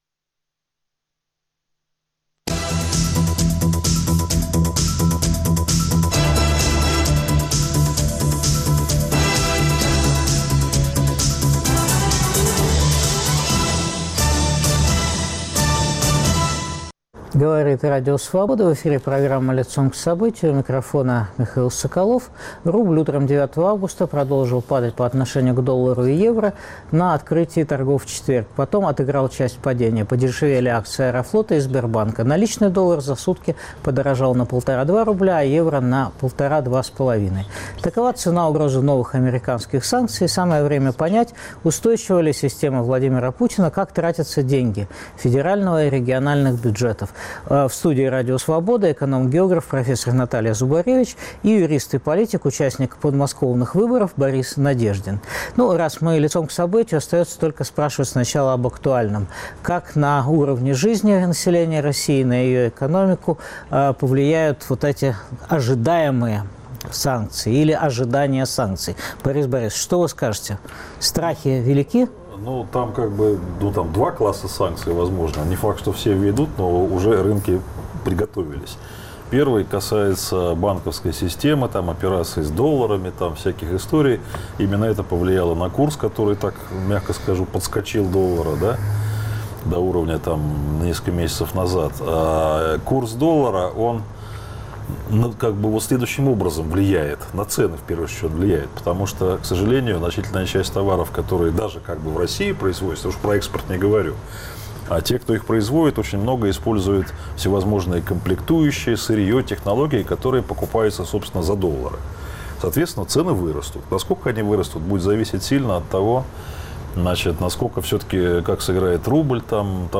Обсуждают доктор географических наук Наталья Зубаревич, юрист и политик Борис Надеждин.